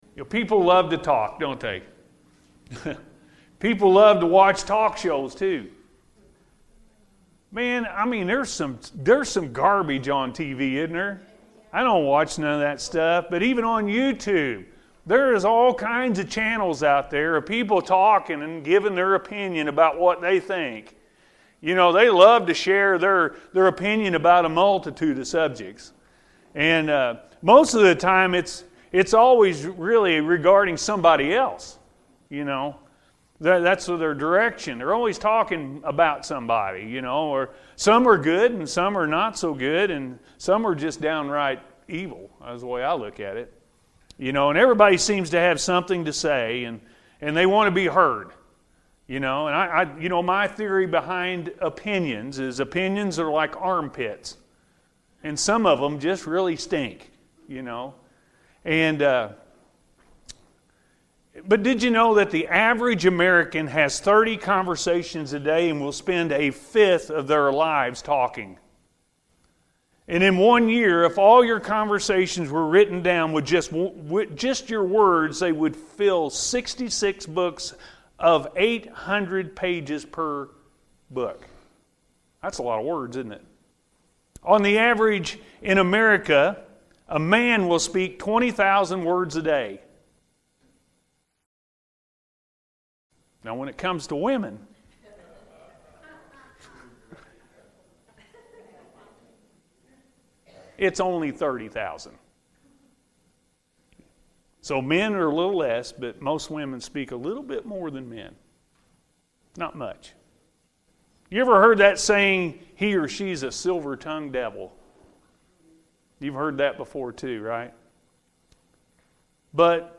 Taming The Tongue-A.M. Service – Anna First Church of the Nazarene